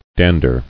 [dan·der]